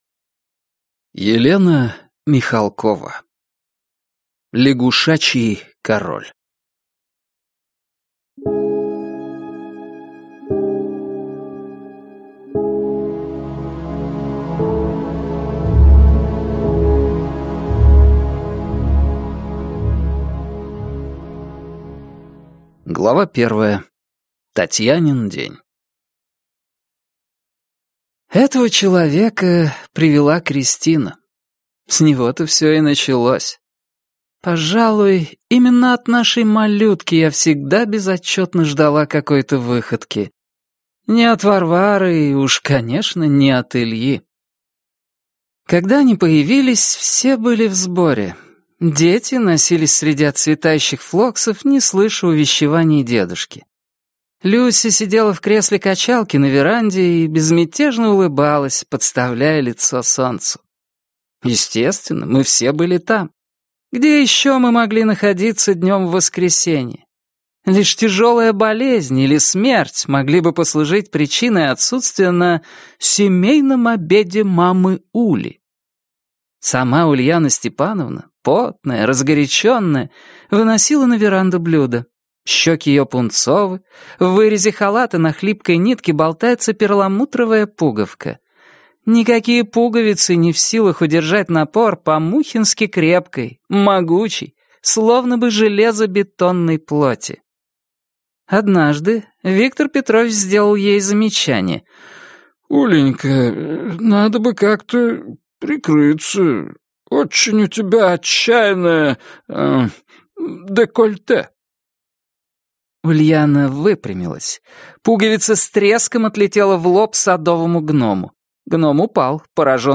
Аудиокнига Лягушачий король | Библиотека аудиокниг